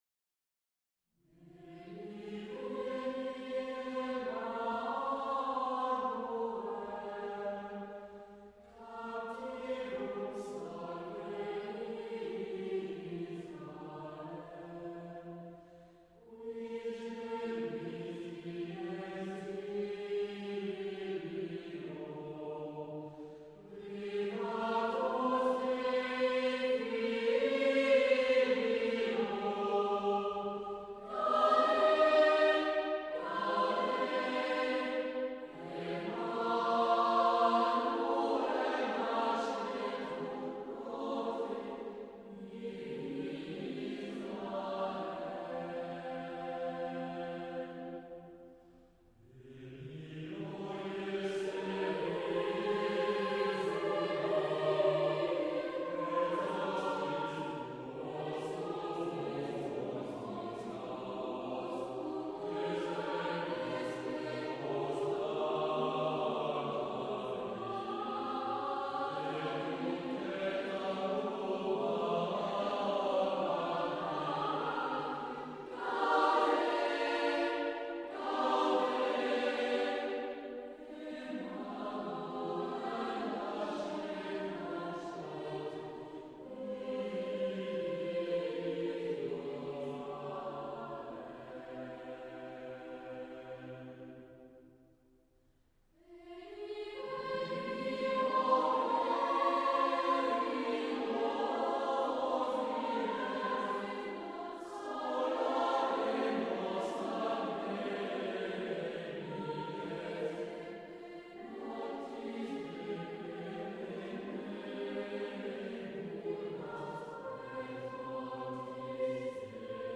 Choeur d’Enfants de la Maitrise de la Perverie